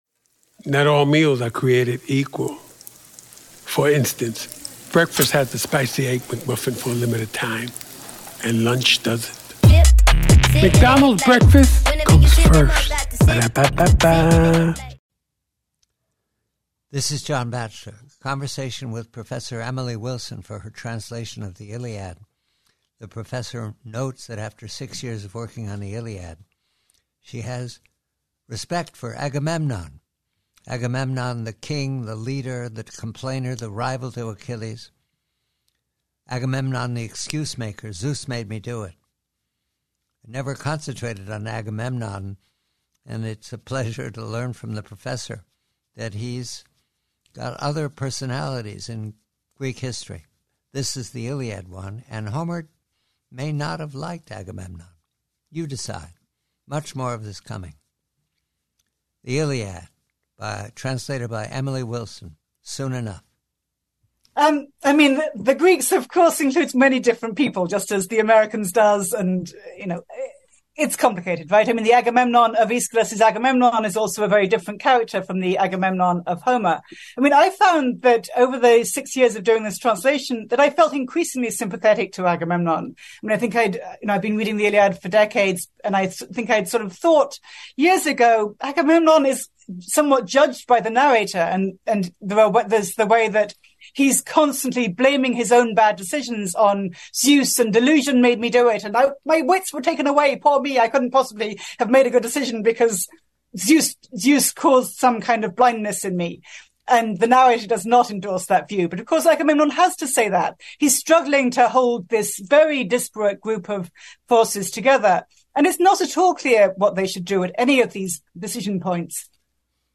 PREVIEW: HOMER'S ILIAD: A conversation with Professor Emily Wilson reveals how six years of translation work led her to develop respect and sympathy for the troubled character of Agamemnon.